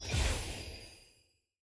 sfx-loot-crafter-button-click.ogg